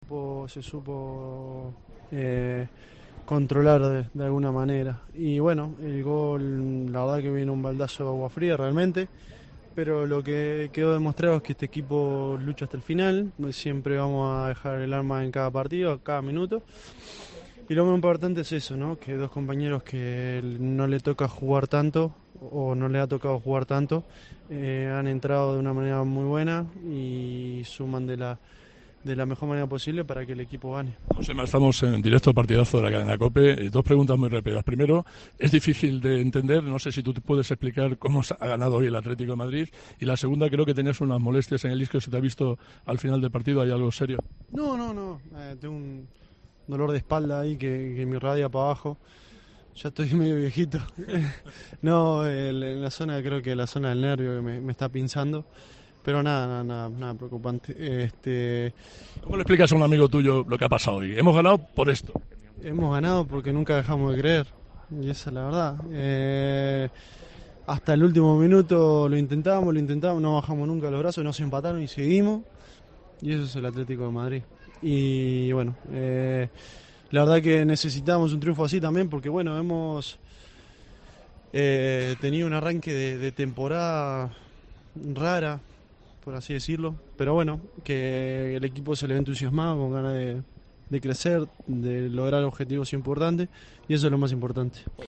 AUDIO: El central ha atendido a El Partidazo de COPE en zona mixta tras la victoria de su equipo ante el Oporto.